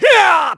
Clause_ice-Vox_Attack4_kr.wav